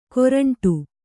♪ koraṇṭu